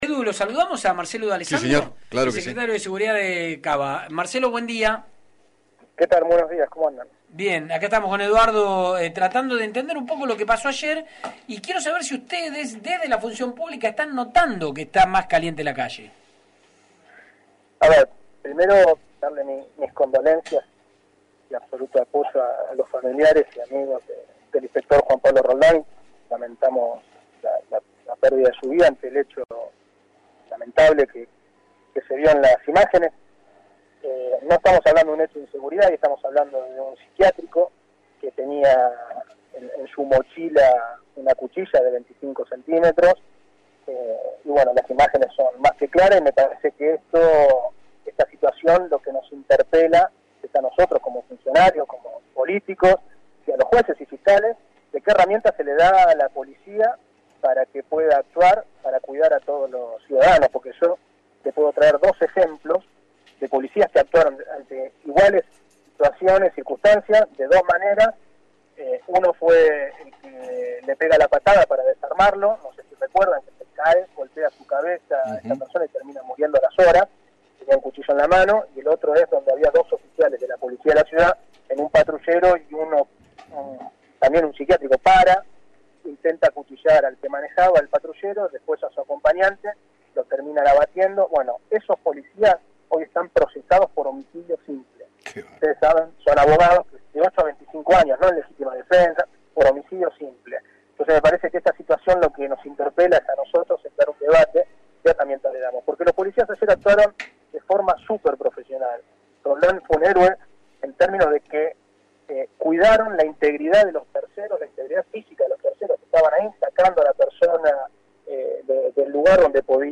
Marcelo D’Alessandro, Secretario de Justicia y Seguridad de la Ciudad Autónoma de Buenos Aires, dialogó con Eduardo Feinmann sobre la utilización de las pistolas Taser en situaciones como la de ayer